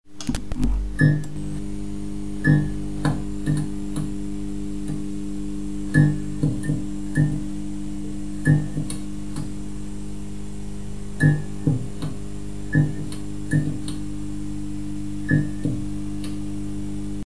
Звуки мерцания
Погрузитесь в мир загадочных аудиоэффектов.